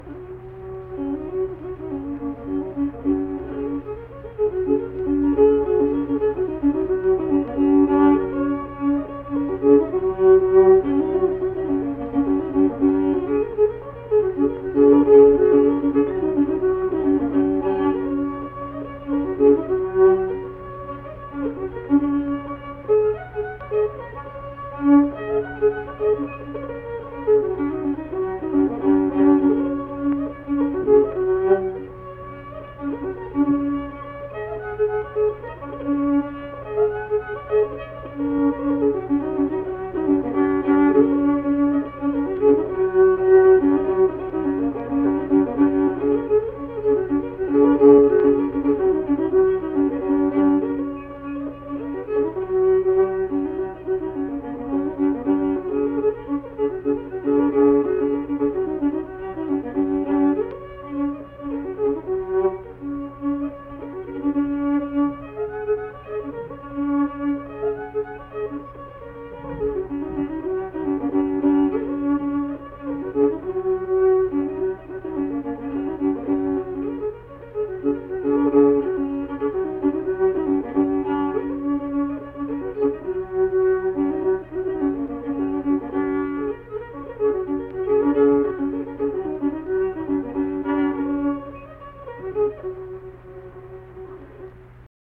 Unaccompanied fiddle music
Verse-refrain 2(2). Performed in Ziesing, Harrison County, WV.
Instrumental Music
Fiddle